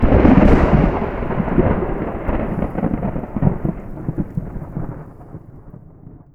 Thunder 5.wav